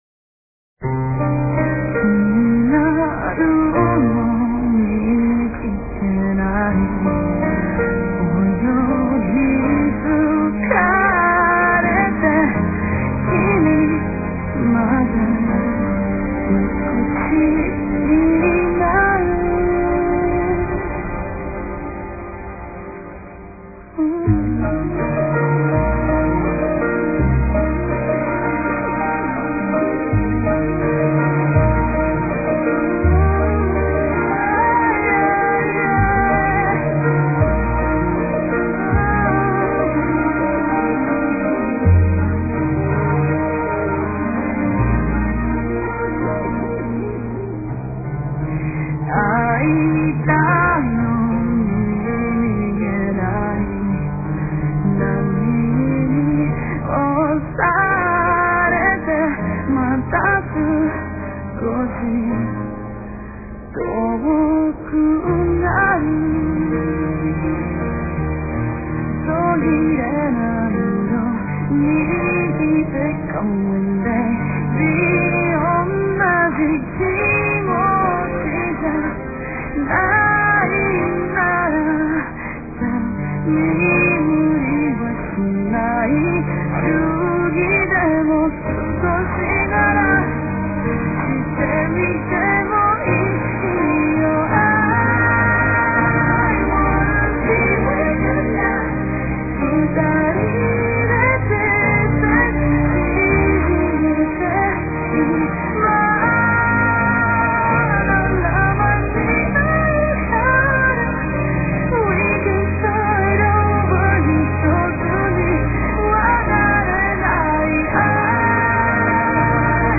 no final tem um Link para Abrir a Música que é Cantada.